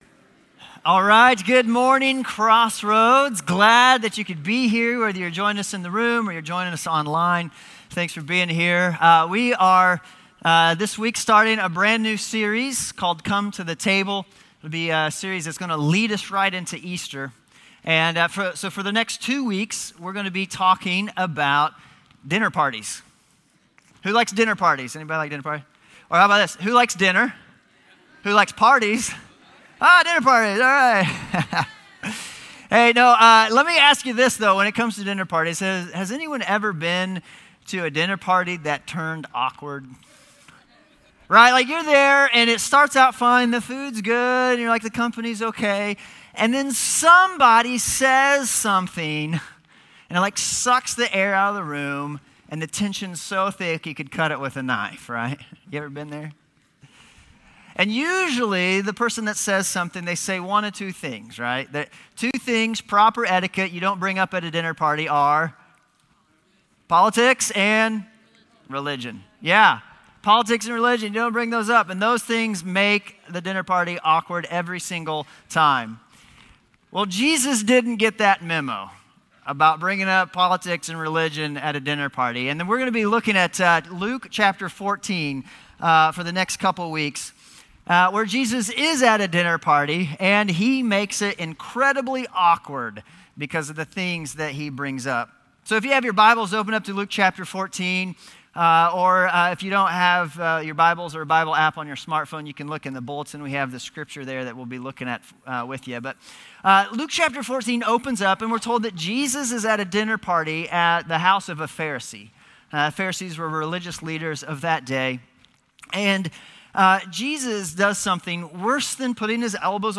Crossroads Community Church - Audio Sermons 2019-04-07 - Come to the Table Play Episode Pause Episode Mute/Unmute Episode Rewind 10 Seconds 1x Fast Forward 30 seconds 00:00 / 40:36 Subscribe Share RSS Feed Share Link Embed